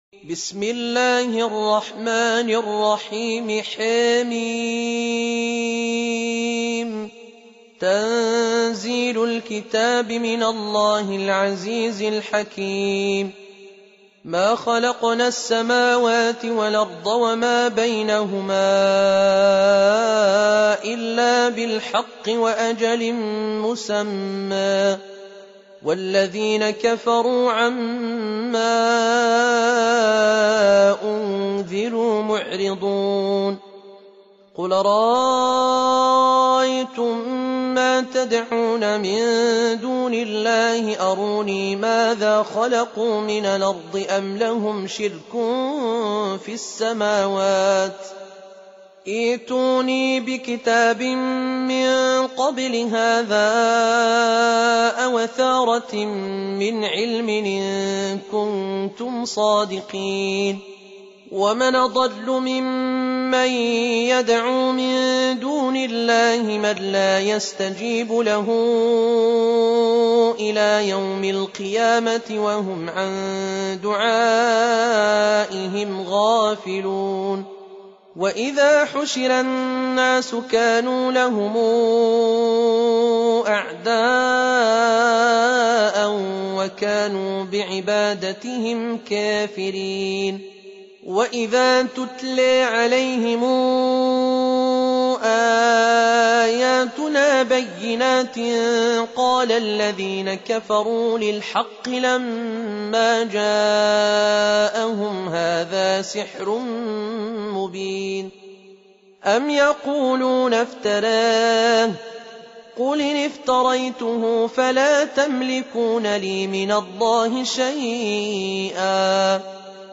Surah Sequence تتابع السورة Download Surah حمّل السورة Reciting Murattalah Audio for 46. Surah Al-Ahq�f سورة الأحقاف N.B *Surah Includes Al-Basmalah Reciters Sequents تتابع التلاوات Reciters Repeats تكرار التلاوات